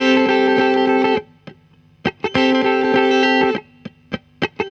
TR GTR 1.wav